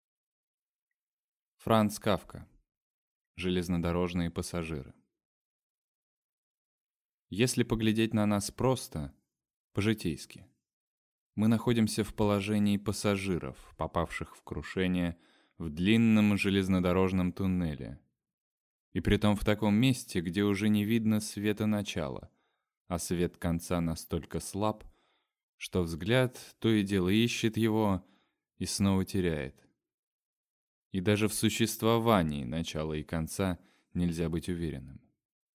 Аудиокнига Железнодорожные пассажиры | Библиотека аудиокниг